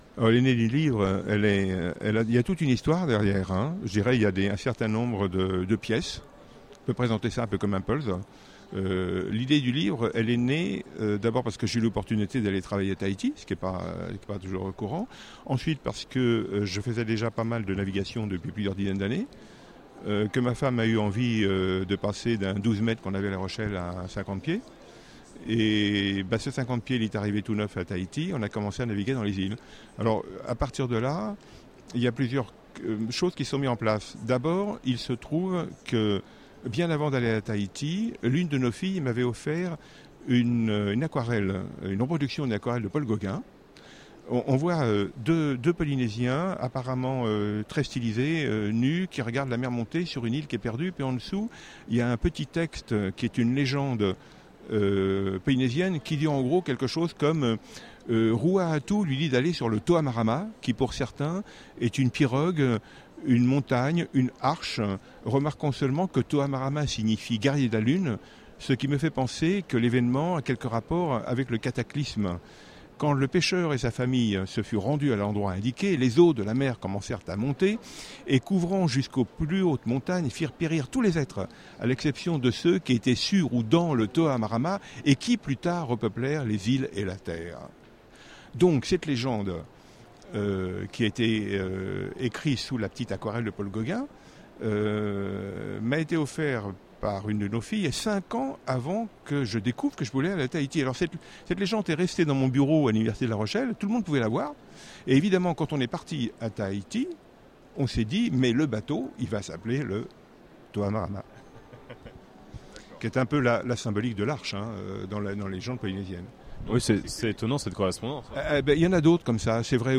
Voici une interview audio pour découvrir cet auteur.